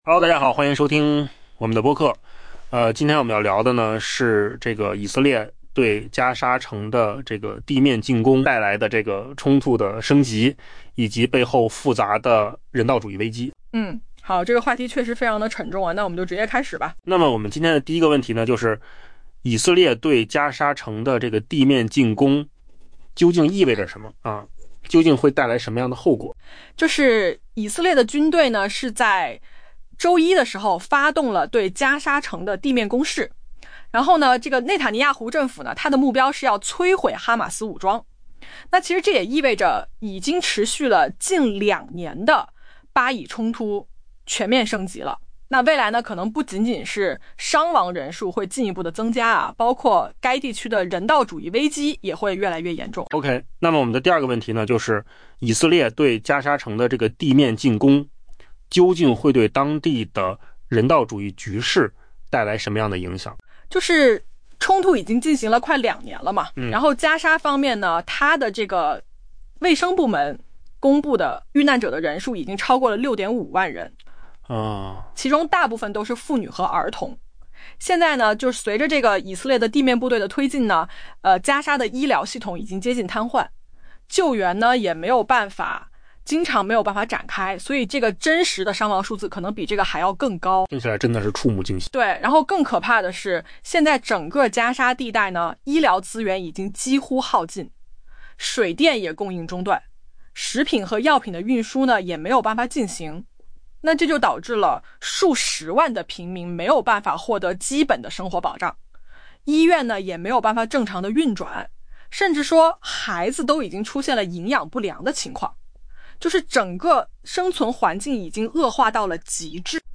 【文章来源：金十数据】AI播客：换个方
AI 播客：换个方式听新闻 下载 mp3 音频由扣子空间生成 以色列官员称，以军周一发动地面攻势占领加沙城，内塔尼亚胡政府表示此举旨在根除哈马斯。